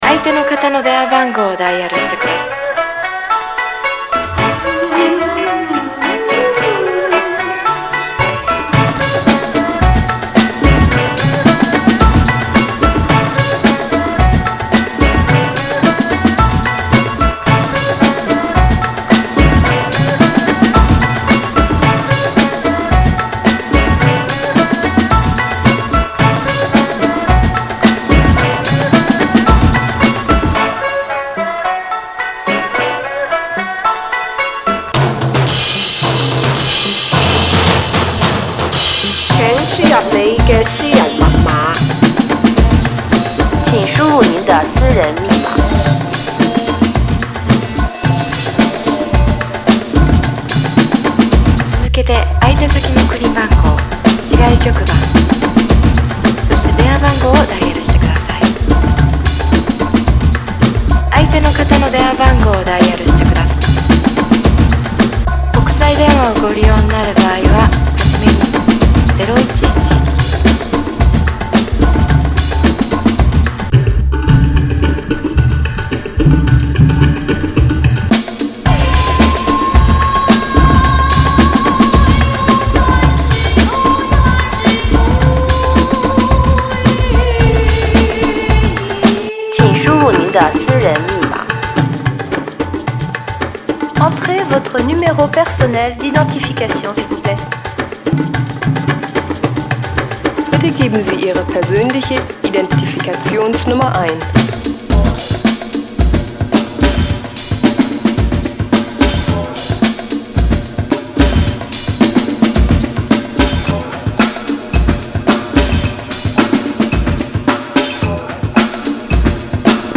Lots of beats from percussionists around the world.